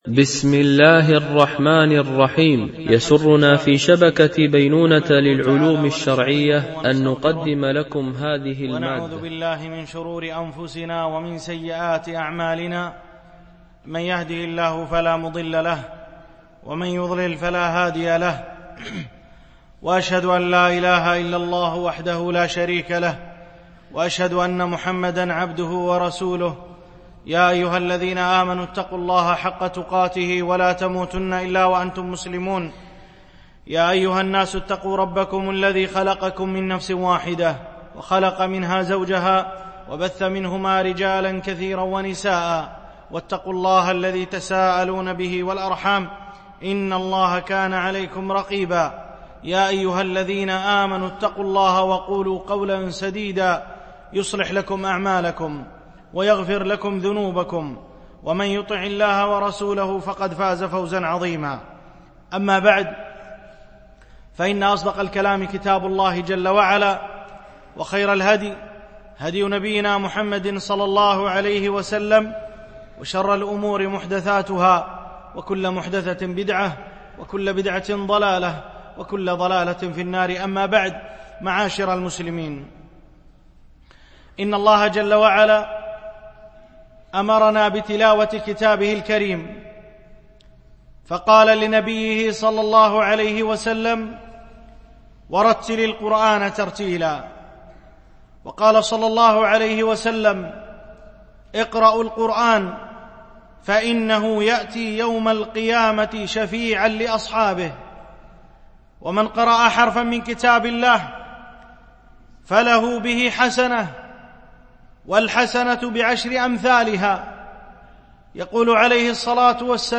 دروس مسجد عائشة